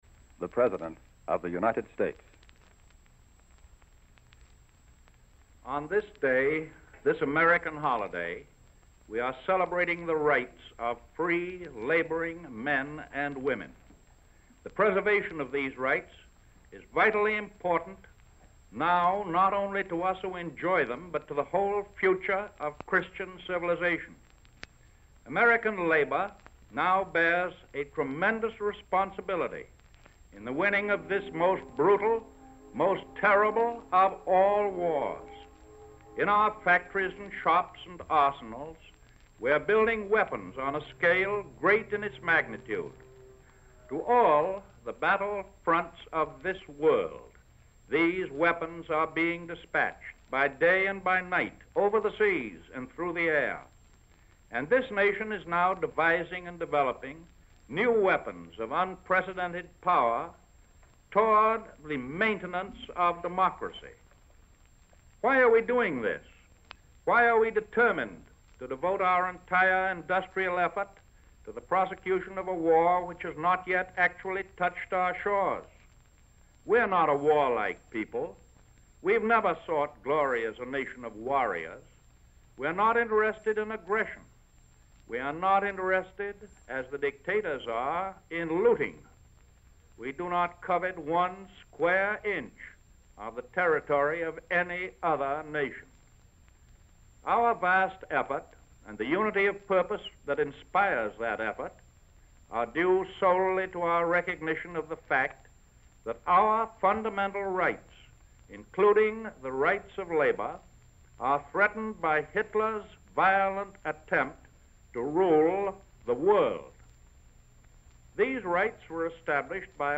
Franklin Delano Roosevelt (FDR) - Labor Day Address (September 1st, 1941)